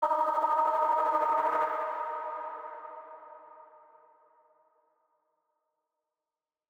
nightcrawler pad stab.wav